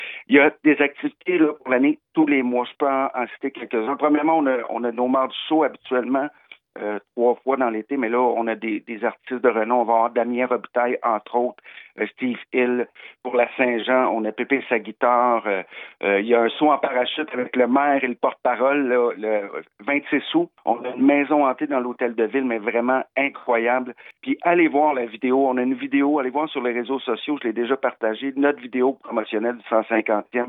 Depuis quelques semaines, Farnham se transforme afin d’accueillir cette année, qui se voudra historique, selon le maire Patrick Melchior, qui nous donne quelques détails sur la programmation.